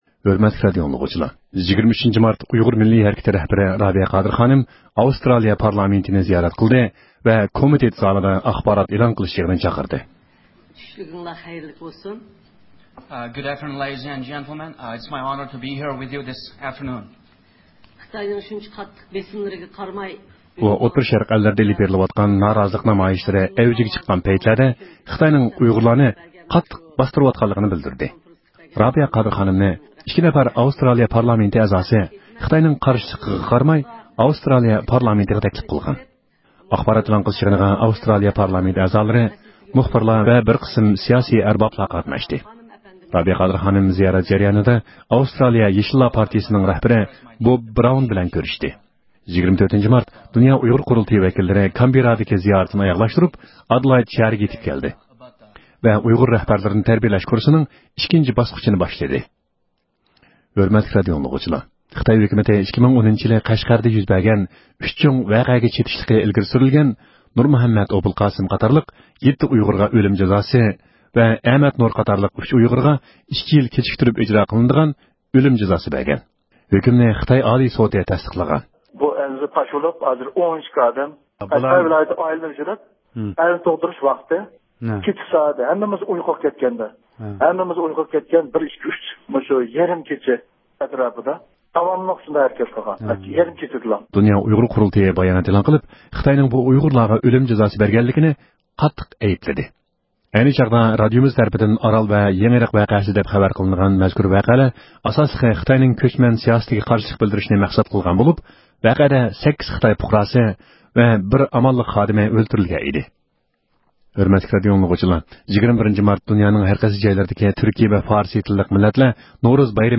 ئەركىن ئاسىيا رادىئوسى ئۇيغۇر بۆلۈمىنىڭ مۇشۇ بىر ھەپتە جەريانىدا ئېلان قىلغان ئۇيغۇرلارغا مۇناسىۋەتلىك بىر قىسىم مۇھىم خەۋەرلىرى.